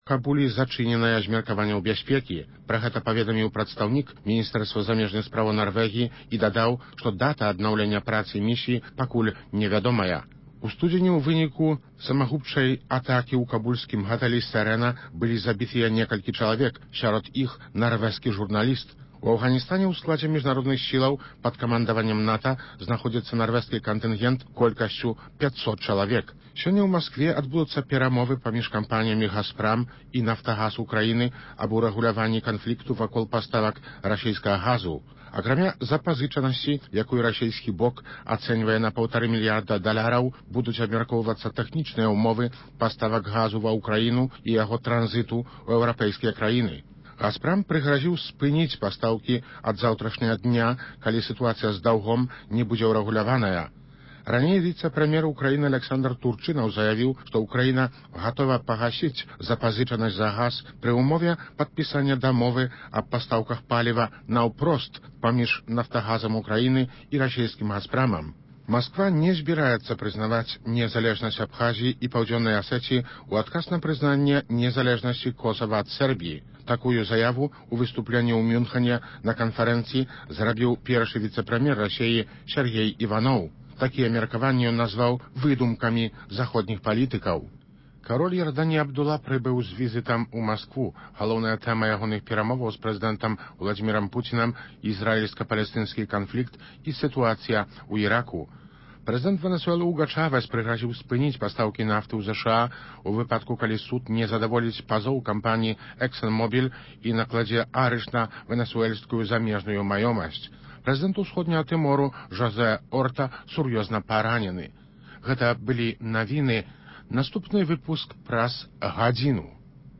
Ці мае Расея пляны палітычнага паглынаньня Беларусі? Над гэтымі праблемамі ў “Праскім акцэнце” разважаюць палітолягі